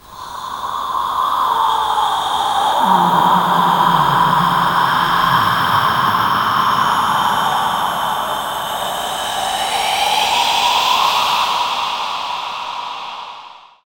Index of /90_sSampleCDs/Optical Media International - Sonic Images Library/SI2_SI FX Vol 7/SI2_Gated FX 7
SI2 HOOSH03R.wav